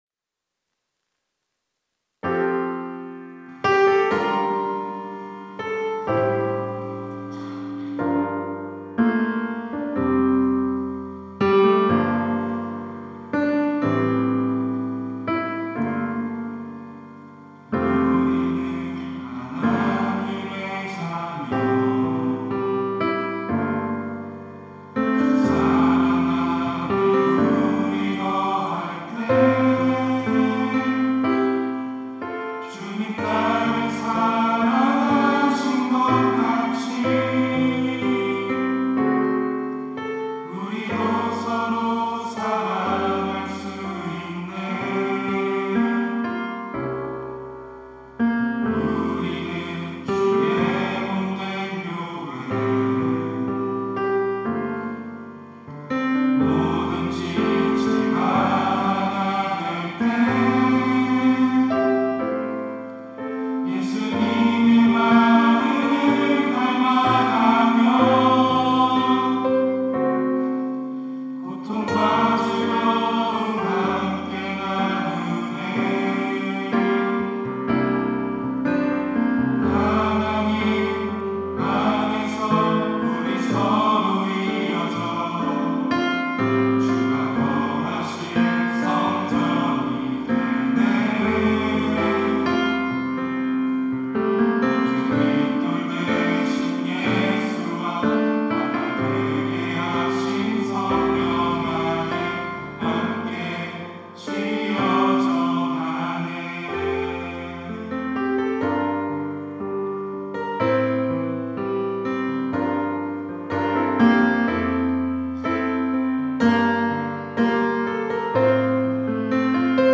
특송과 특주 - 함께 지어져가네
청년부 2024 임원, 교역자